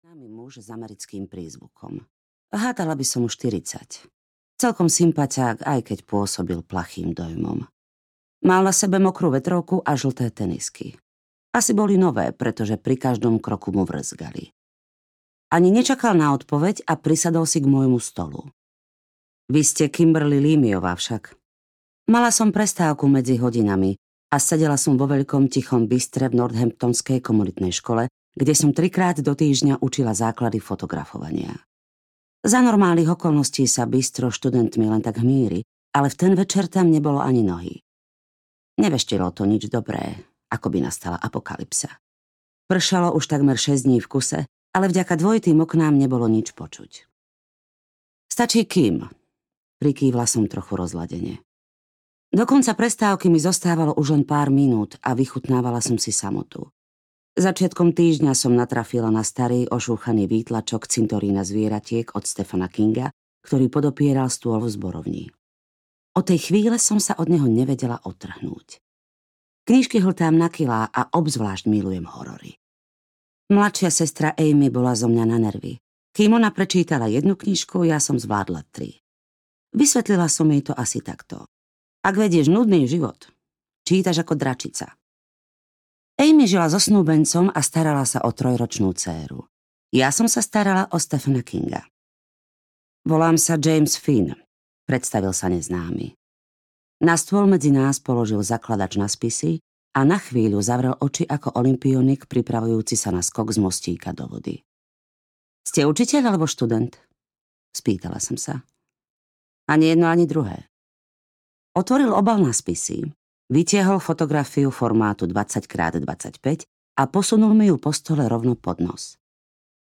Nezvestná audiokniha
Ukázka z knihy